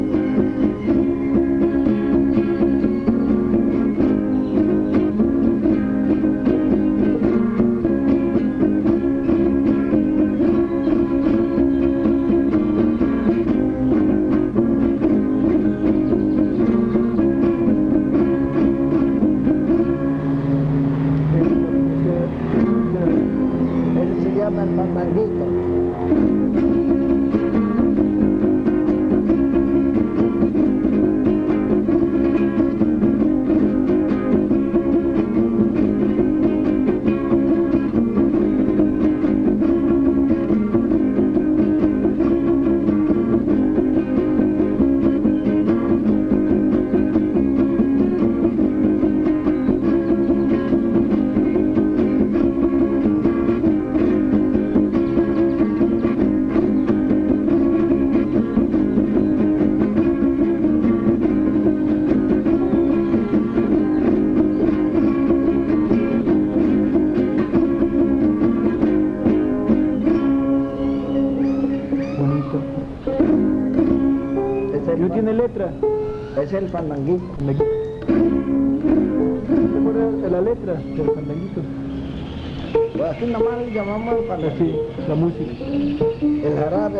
• Son de Santiago (Grupo musical)
Fiesta de Santiago Tuxtla : investigación previa